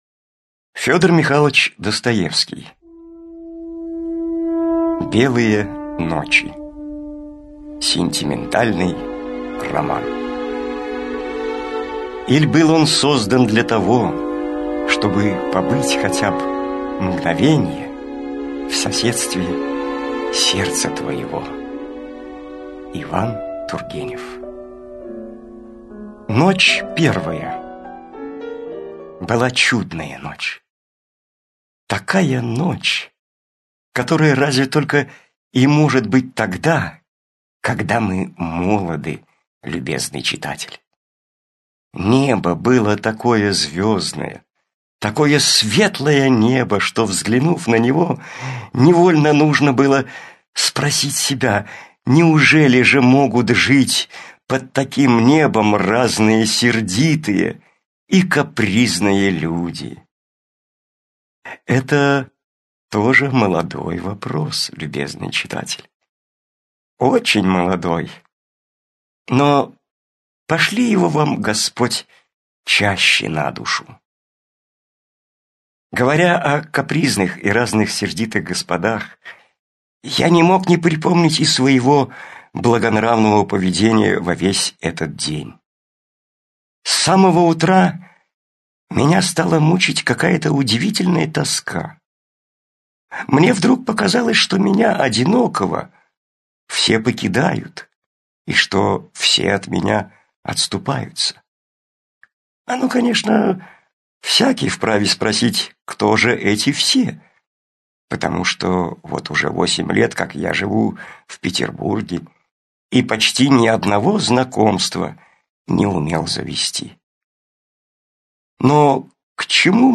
Аудиокнига Белые ночи - купить, скачать и слушать онлайн | КнигоПоиск